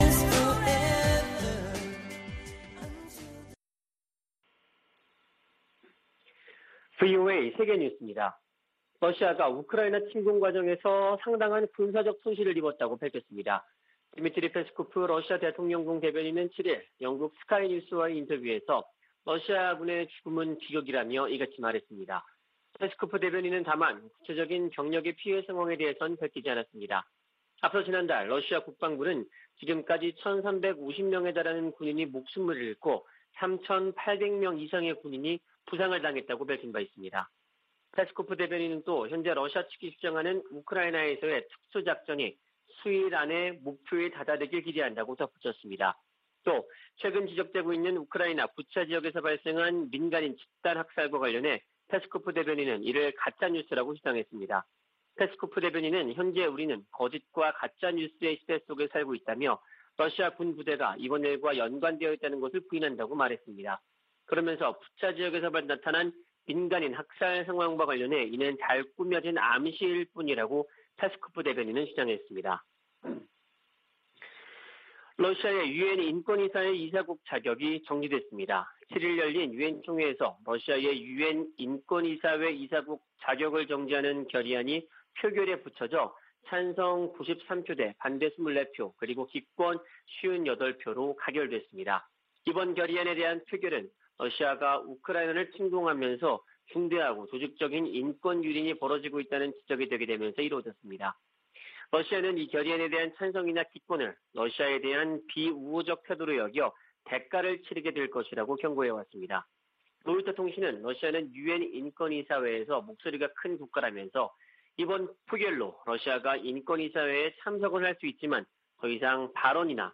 VOA 한국어 아침 뉴스 프로그램 '워싱턴 뉴스 광장' 2022년 4월 8일 방송입니다. 웬디 셔먼 미 국무부 부장관은 핵무장한 북한은 중국의 이익에도 부합하지 않는다며, 방지하기 위한 중국의 협력을 촉구했습니다. 미 국방부는 한국 내 전략자산 배치와 관련해 한국과 협력할 것이라고 밝혔습니다. 윤석열 한국 대통령 당선인이 캠프 험프리스를 방문해 북한의 핵과 미사일 위협에 강력 대응 의지를 밝혔습니다.